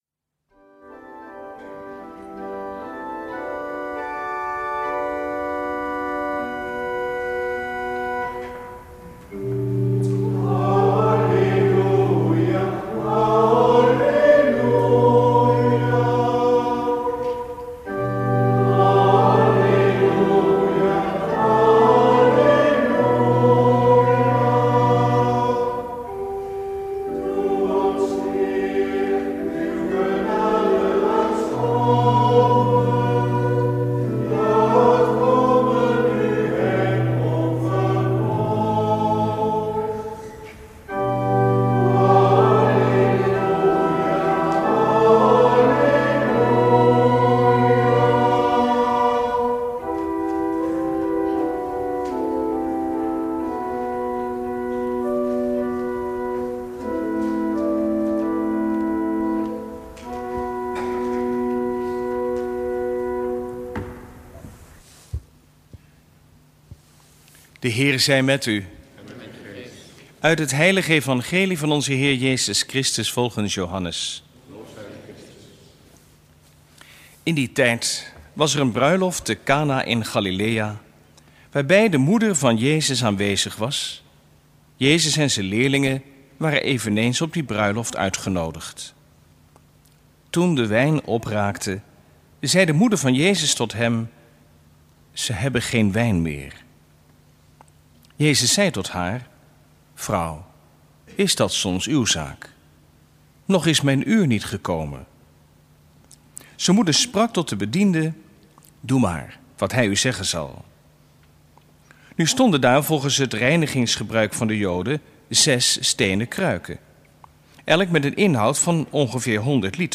Eucharistieviering beluisteren vanuit de Jozefkerk te Wassenaar (MP3)